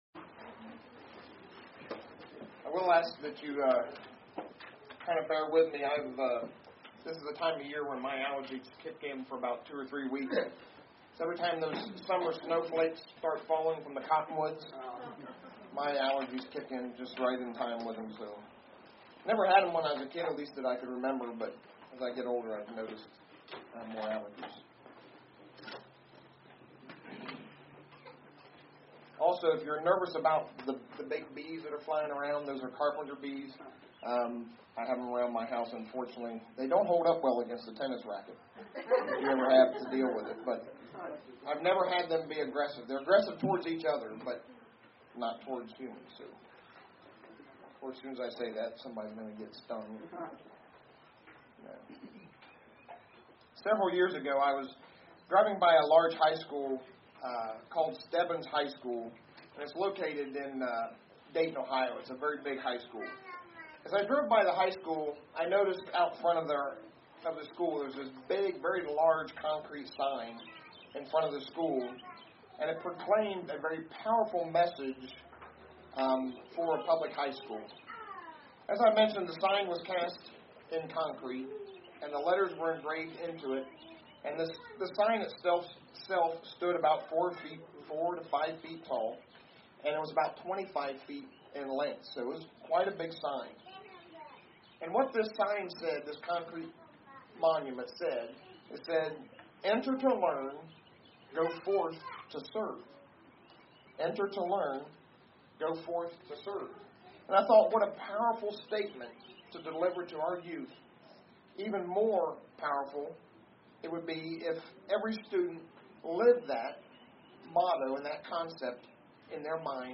As we held services at a local park